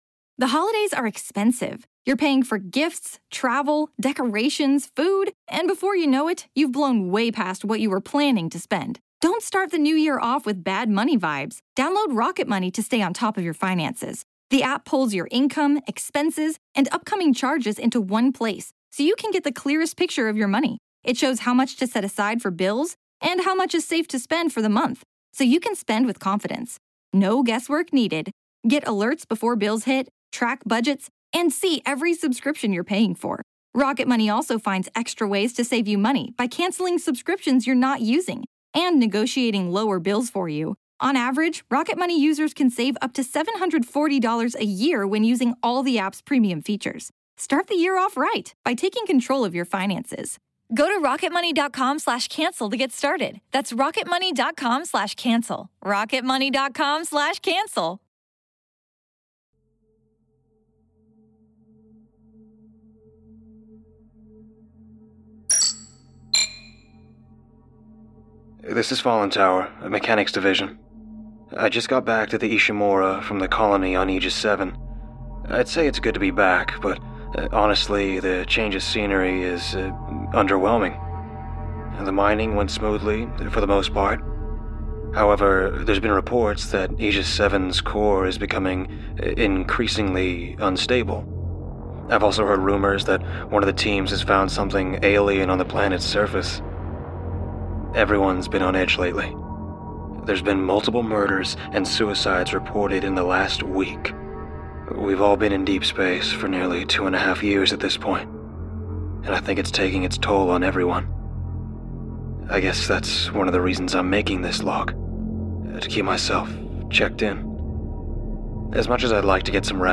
*Volume warning at 7:30! Be weary!*